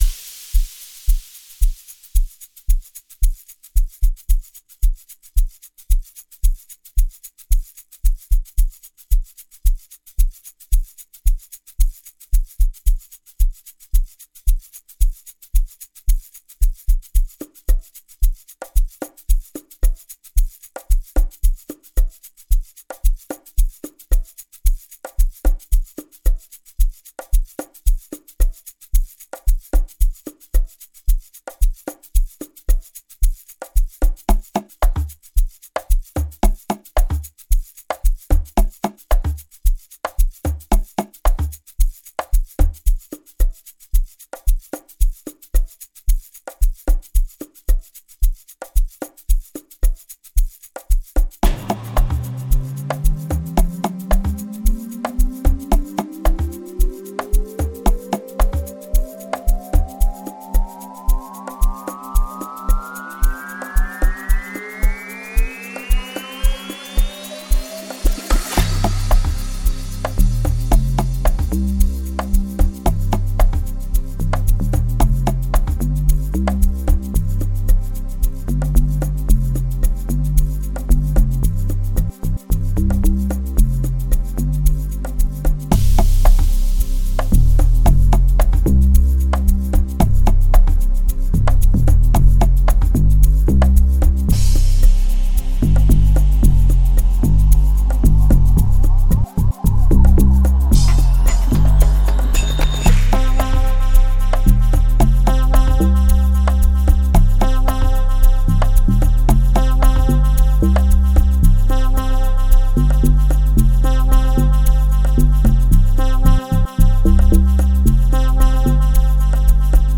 06:36 Genre : Amapiano Size